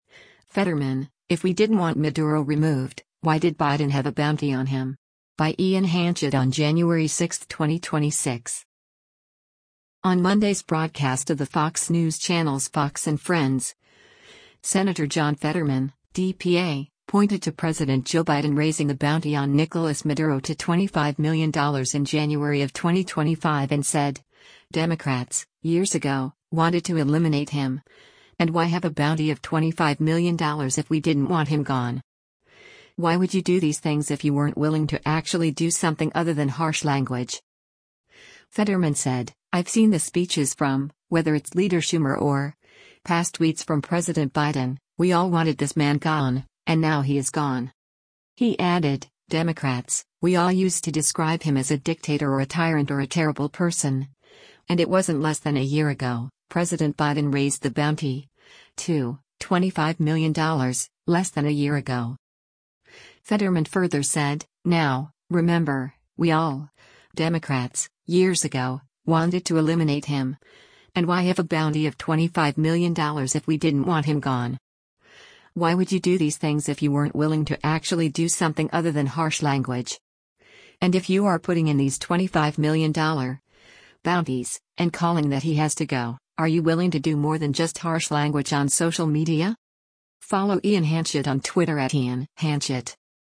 On Monday’s broadcast of the Fox News Channel’s “Fox & Friends,” Sen. John Fetterman (D-PA) pointed to President Joe Biden raising the bounty on Nicolas Maduro to $25 million in January of 2025 and said, “Democrats, years ago, wanted to eliminate him, and why have a bounty of $25 million if we didn’t want him gone?